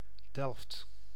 Delft (Dutch pronunciation: [ˈdɛl(ə)ft]
Nl-Delft.ogg.mp3